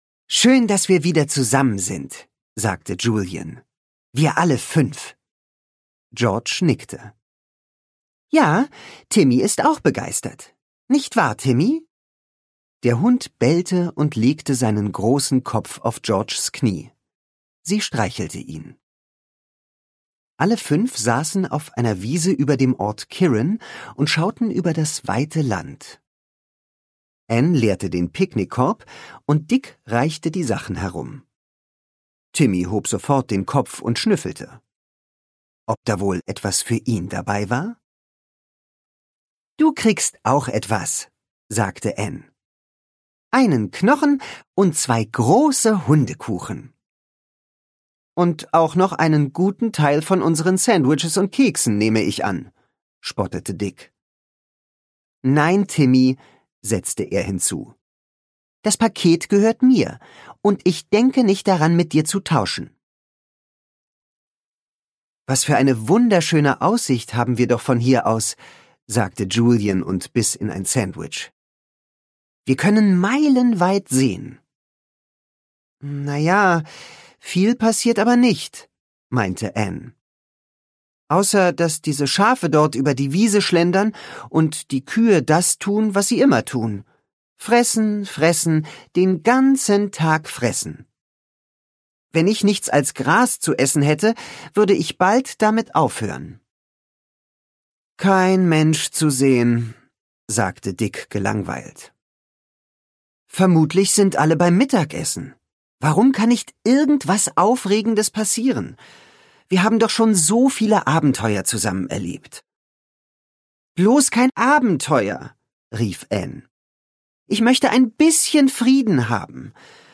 Ungekürzte Lesung cbj audio